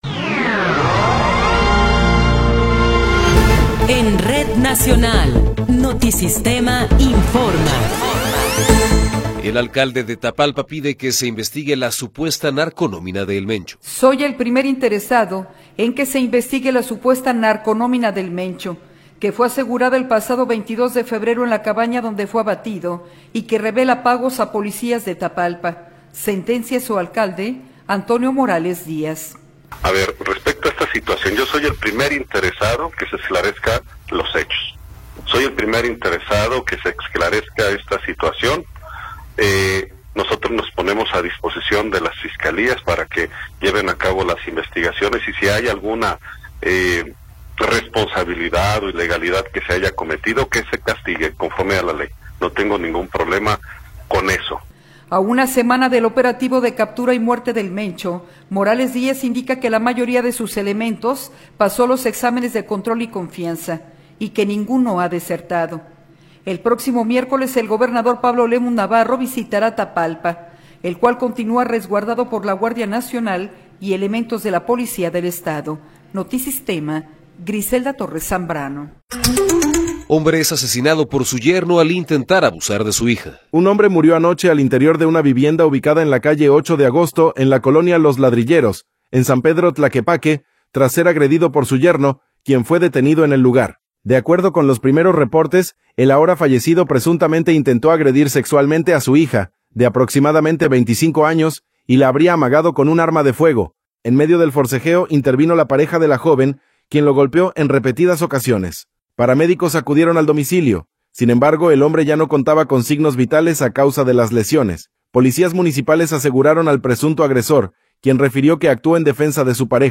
Noticiero 12 hrs. – 2 de Marzo de 2026
Resumen informativo Notisistema, la mejor y más completa información cada hora en la hora.